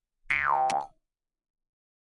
Mouth harp vol. 1 » Mouth harp 8 lower formant staccato up
描述：口琴（通常被称为“犹太人的竖琴”）调到C＃。 用RØDENT2A录制。
Tag: 竖琴 曲调 Mouthharp 共振峰 仪器 传统的 jewsharp 共振峰 弗利